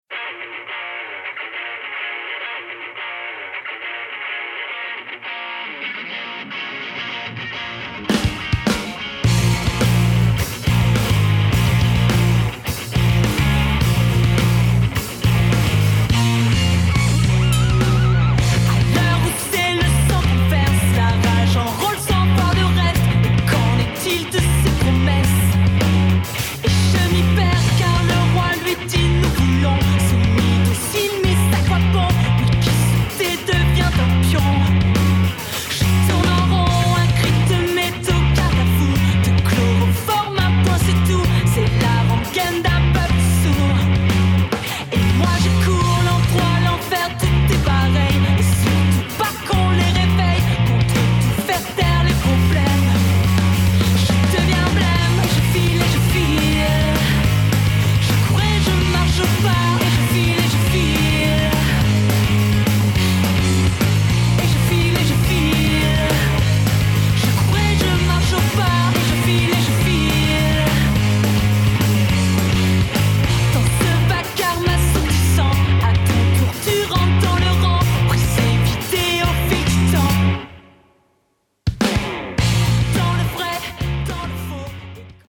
pop rock
Duo pop rock avec une pointe d'électro